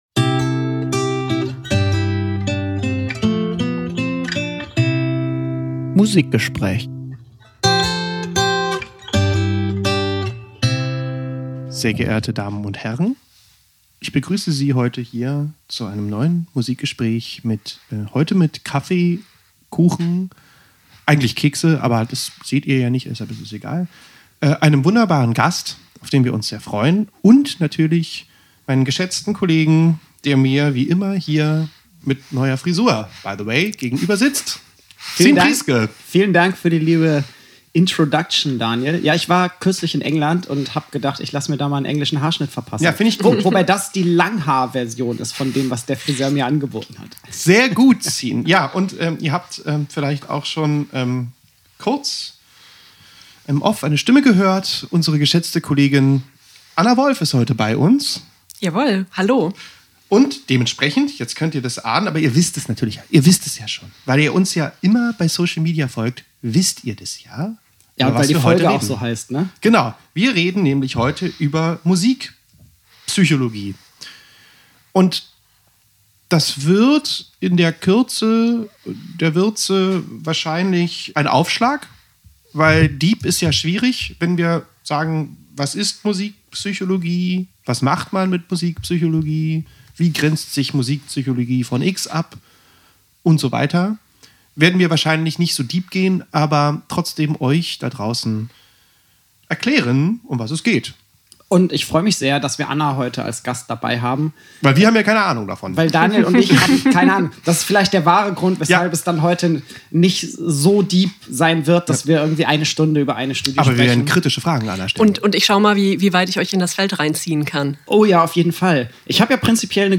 Im aktuellen Musikgespräch